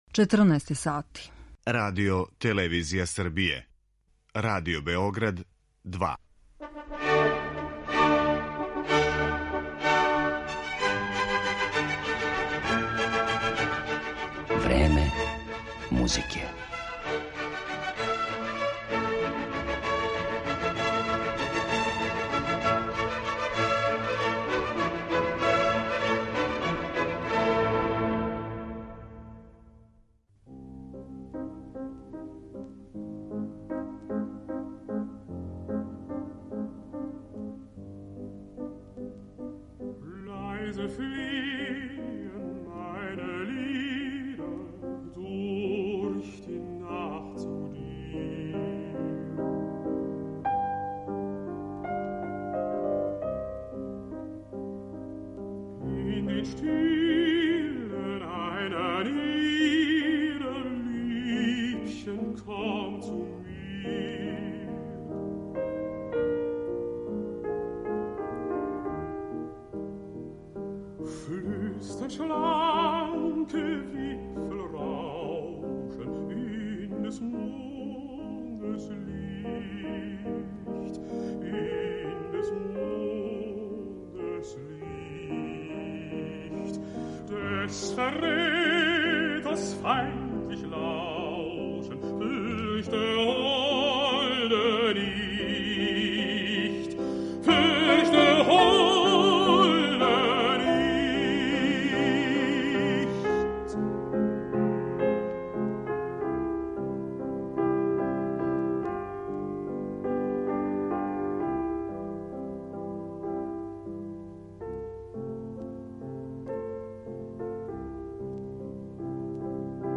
Немачки лирски баритон Дитрих Фишер-Дискау, који је живео од 1925. до 2012, један је од највећих певача свих времена.